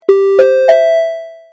SE（アラート）